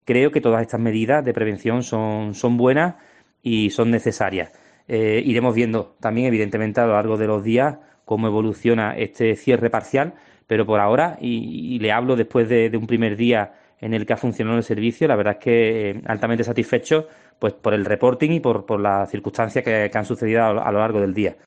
Santiago Galván, alcalde de Zahara de la Sierra